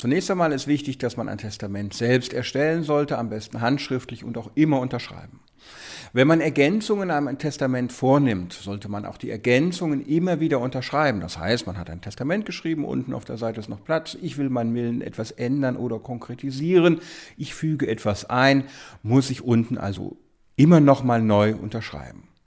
O-Ton + Magazin: Testamentsergänzungen auf Kopien stets unterschreiben